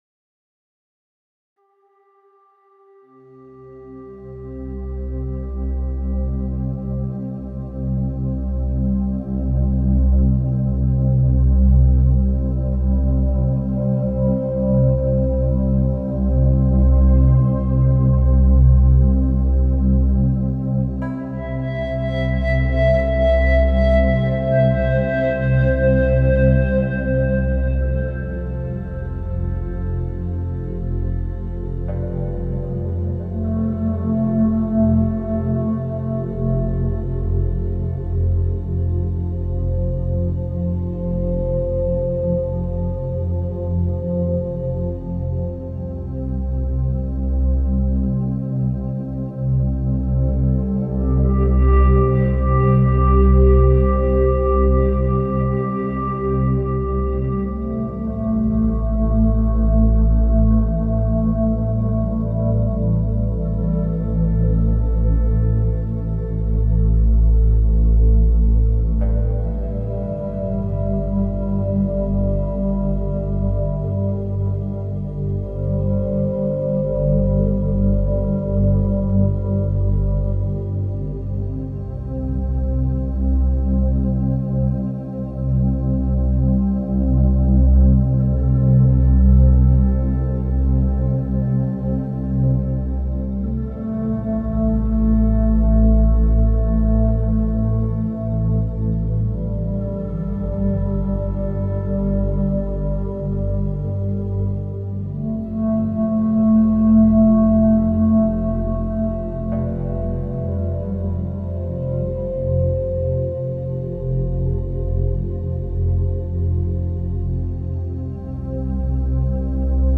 Genre: Downtempo, New Age, Ambient.